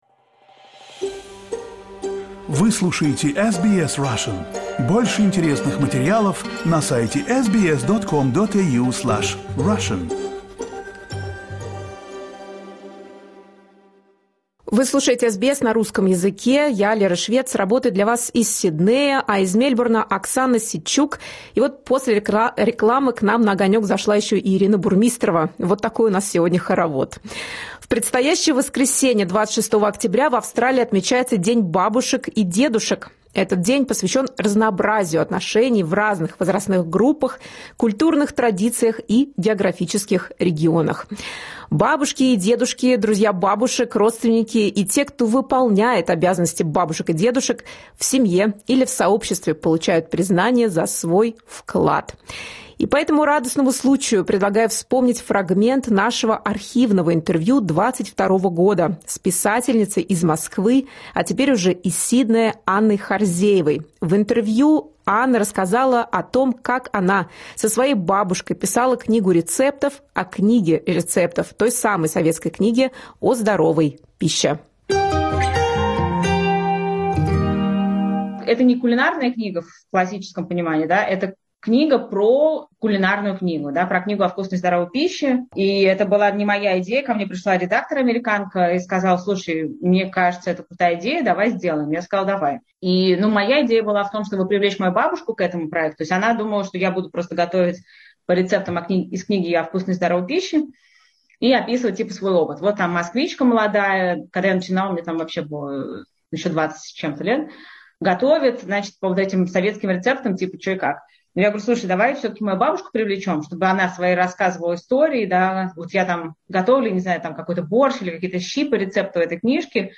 архивное интервью